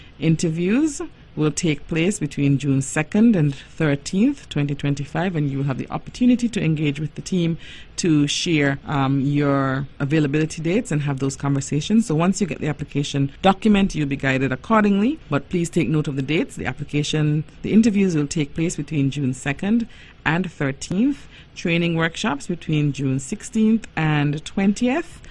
Applications are now open for this year’s Summer Job Attachment Program on Nevis. Speaking about the program was Permanent Secretary in the Ministry of Education, Ms. Zahnela Claxton.